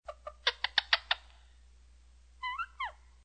coolchirp.mp3